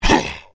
gnoll_mage_attack.wav